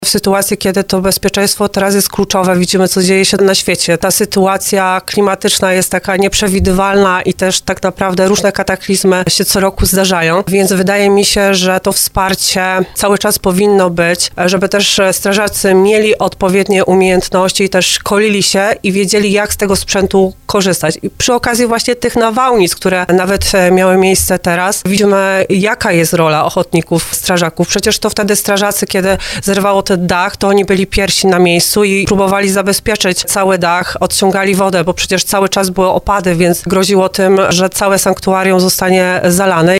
Na antenie RDN Małopolska podsumowała, że od 2019 roku Urząd Marszałkowski przeznaczył już ponad 100 milionów złotych na rozwój i doposażenie jednostek OSP.